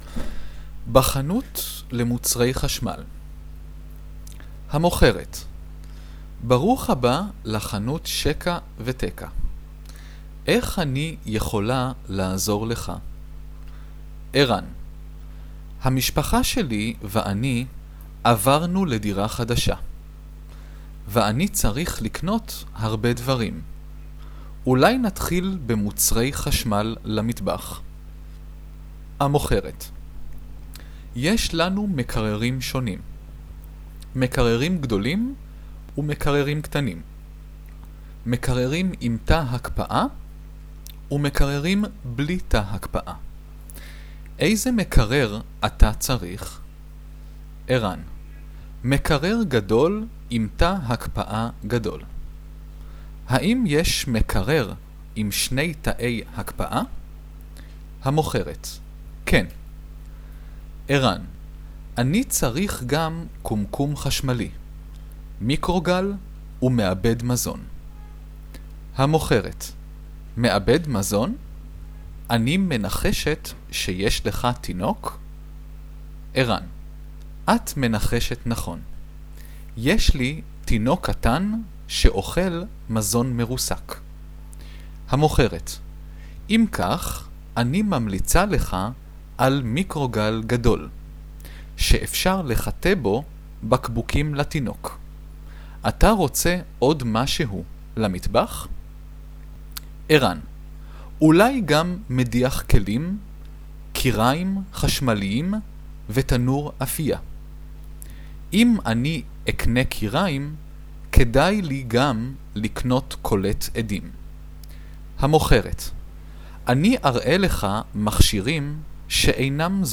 Hebrew Dialouge - The Electronics Store - Ivrit Talk